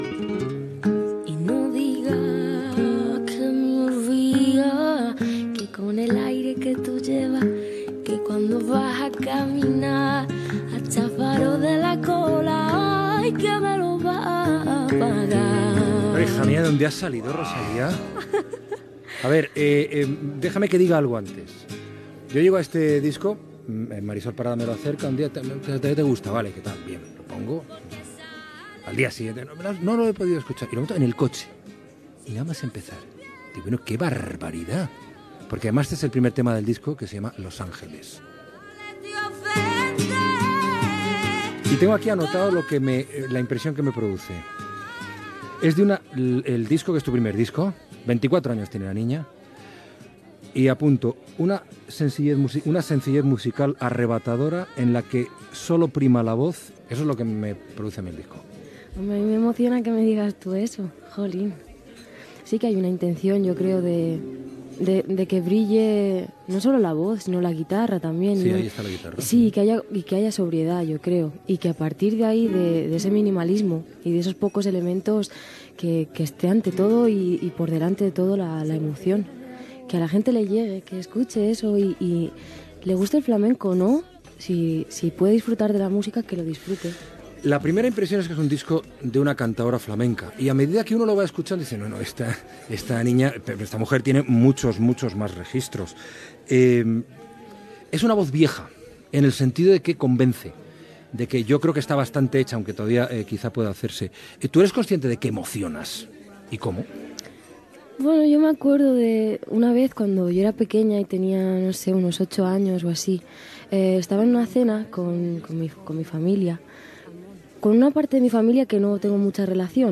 Presentació i fragment d'una entrevista a la cantant Rosalía (Rosalia Vila) quan va editar el seu primer disc "Los ángeles".
Info-entreteniment